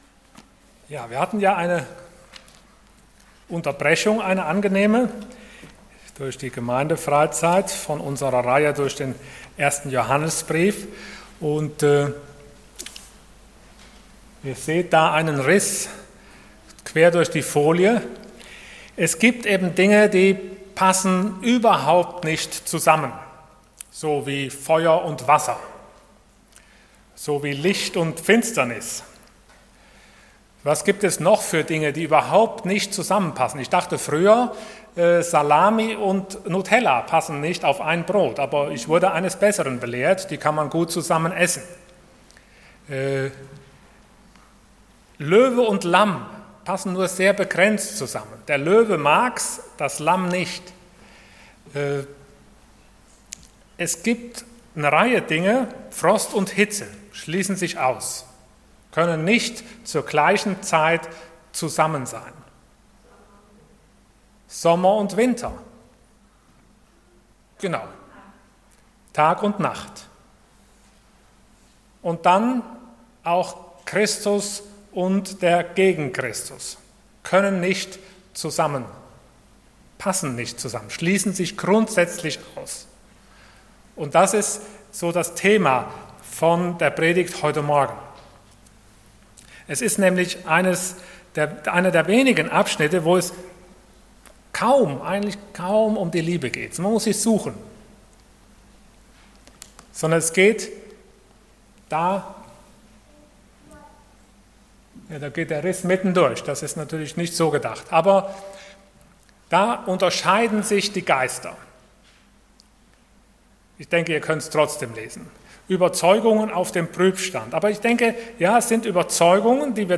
Passage: 1 John 4:1-6 Dienstart: Sonntag Morgen